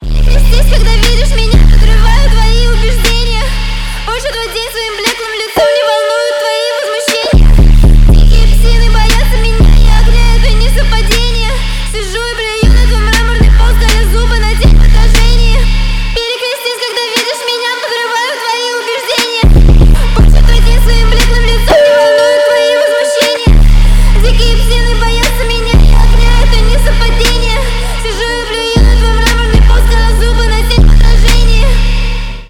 • Качество: 128, Stereo
громкие
русский рэп
женский голос
мощные басы